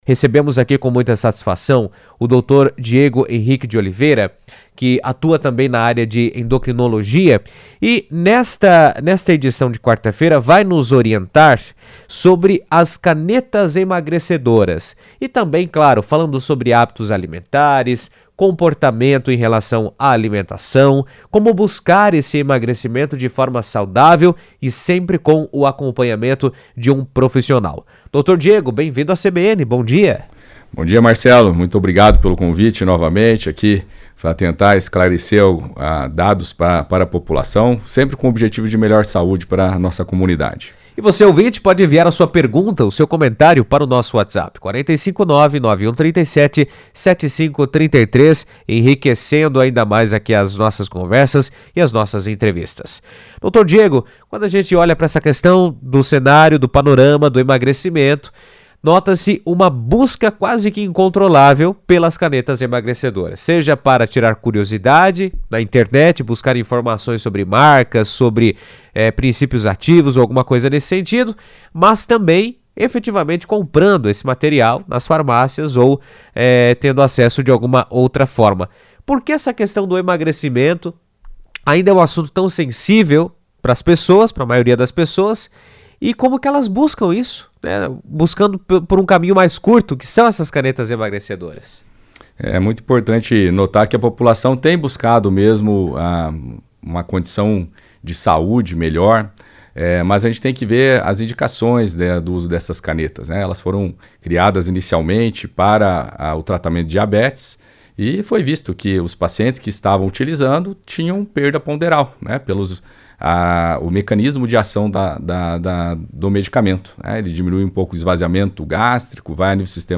falou sobre o tema na CBN Cascavel.